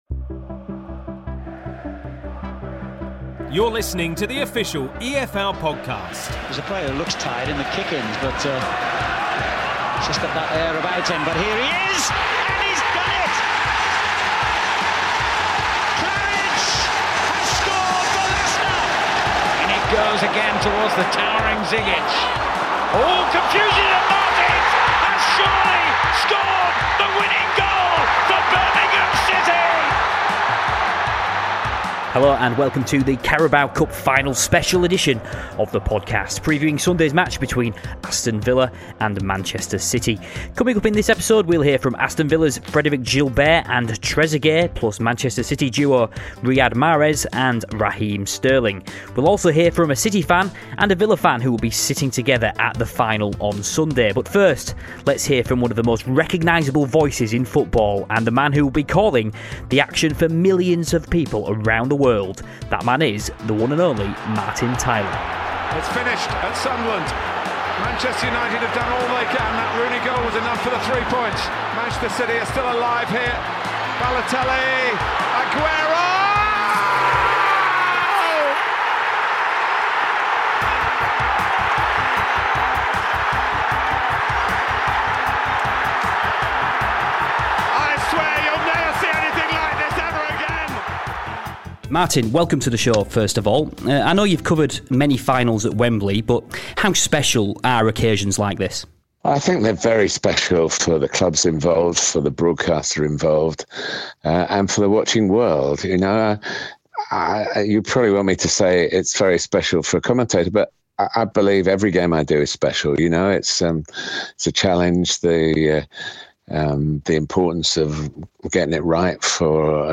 one of football's most iconic voices, Martin Tyler
speaks with a fan of each team